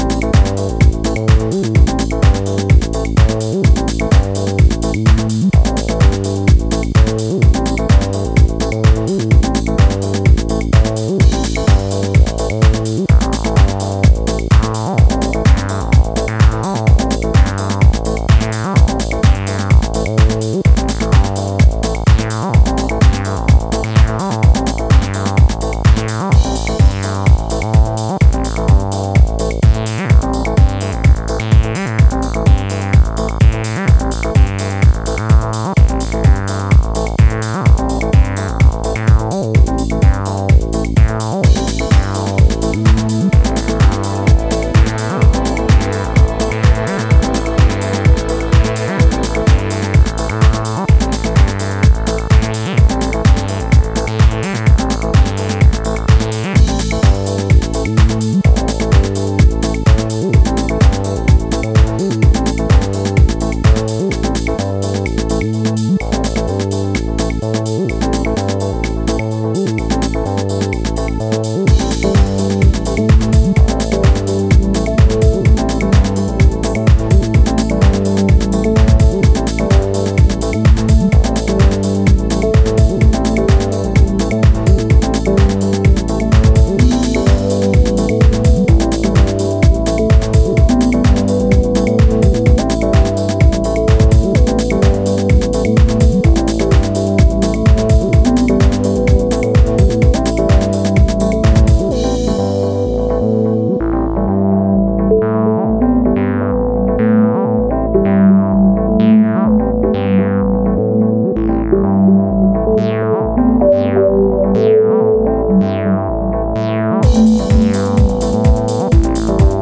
Chicago house